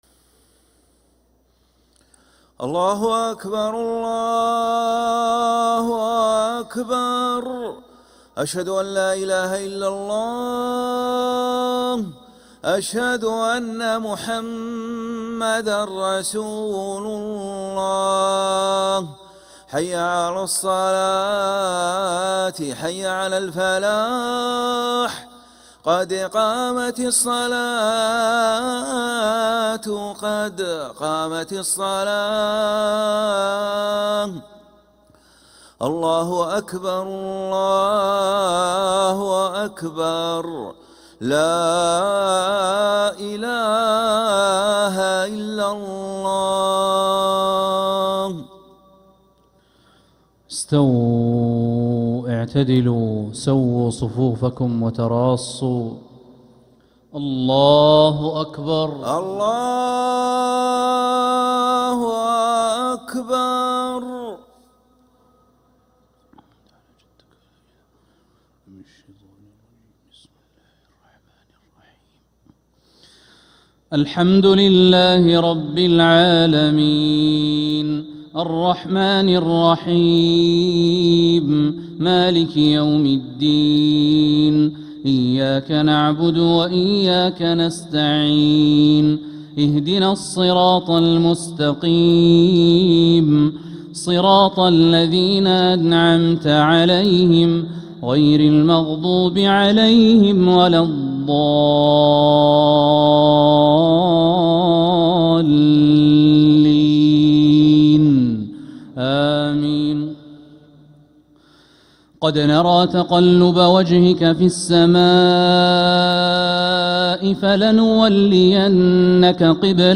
Makkah Isha - 13th April 2026